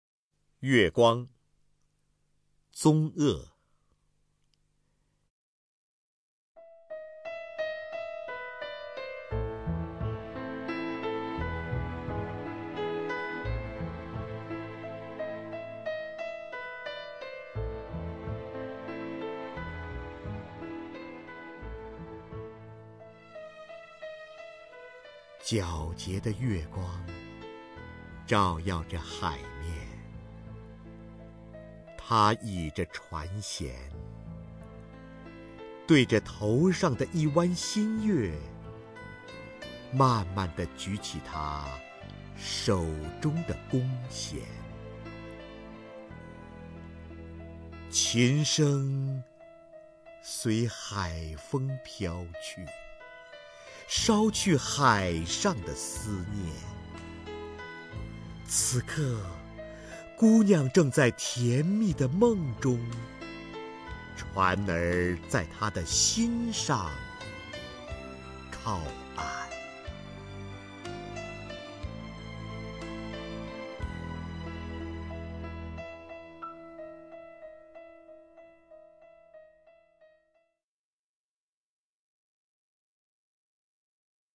瞿弦和朗诵：《月光》(宗鄂)